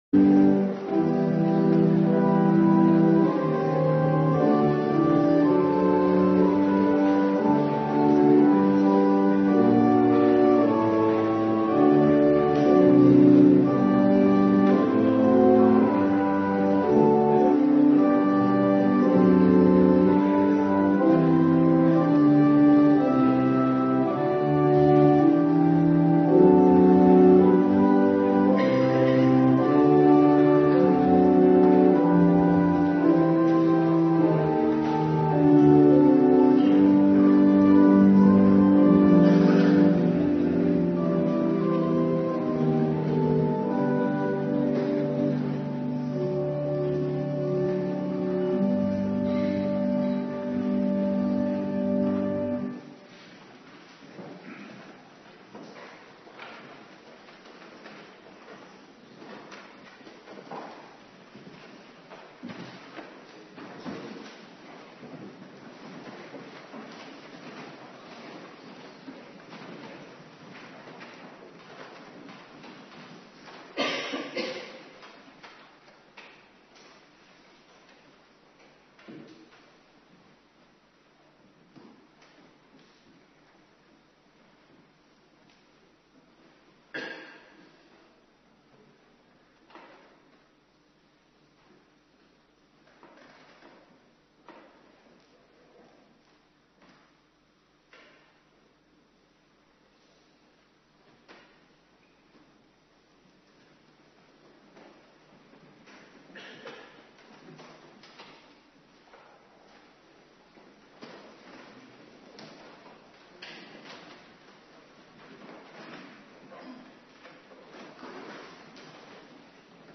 Avonddienst Nabetrachting Heilig Avondmaal
18:30 t/m 20:00 Locatie: Hervormde Gemeente Waarder Agenda